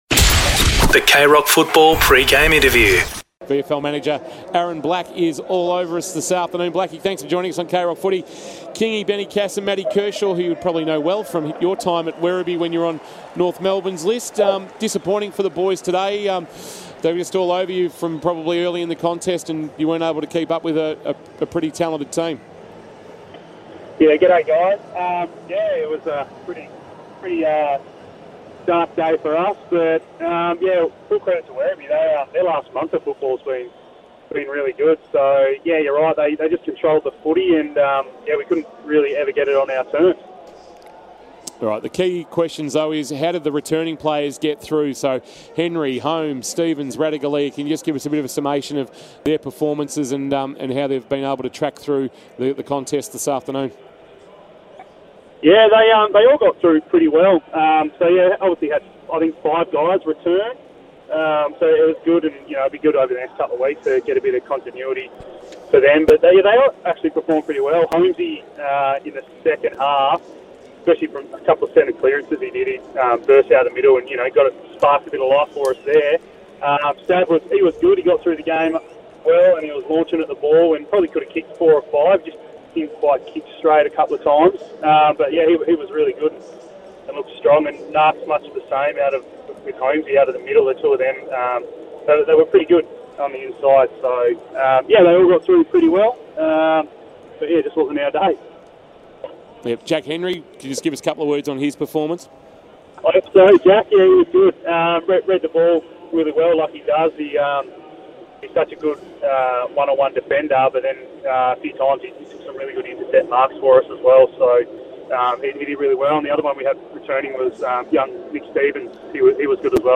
2022 - AFL ROUND 14 - WEST COAST vs. GEELONG: Pre-match Interview